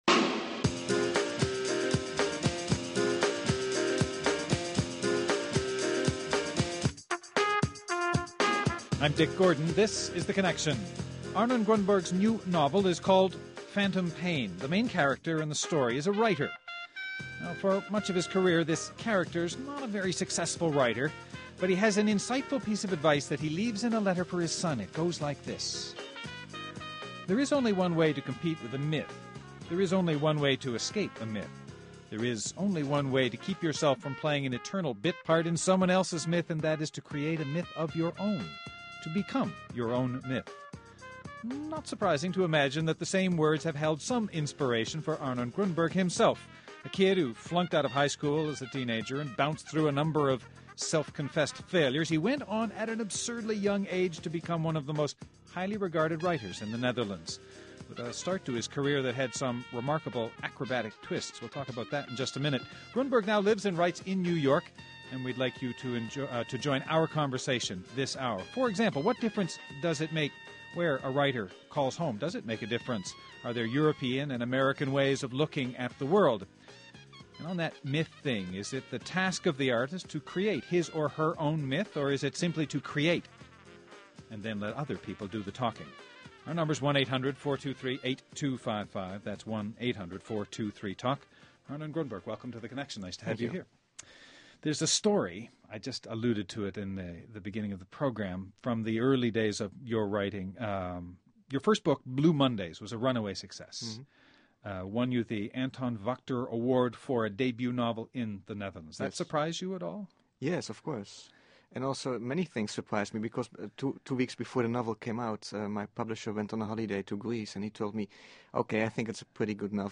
Arnon Grunberg, award-winning Dutch novelist.